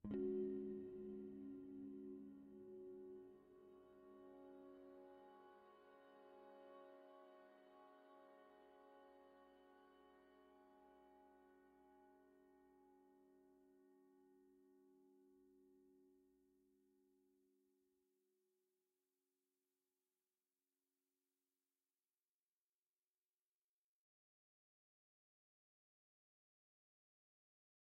piano_piano_string2.ogg